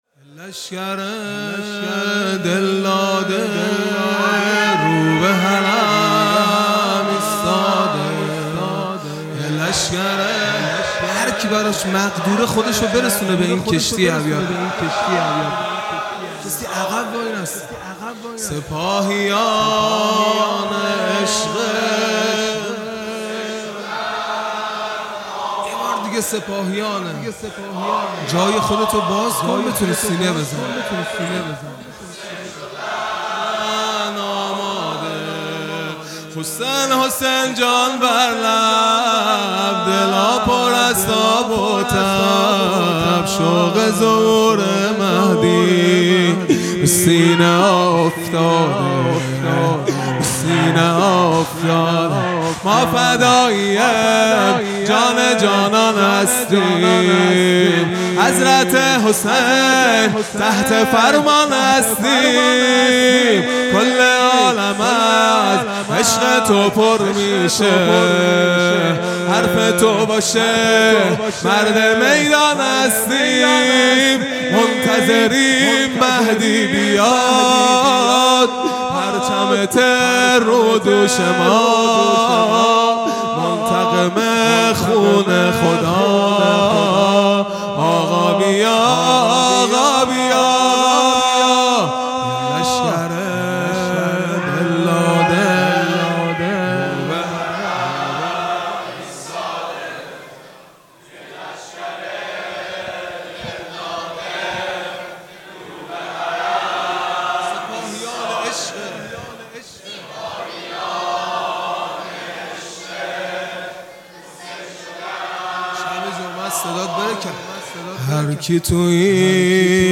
0 0 رجز | یه لشکر دلداده رو به حرم ایستاده
محرم ۱۴۴۴ | شب هفتم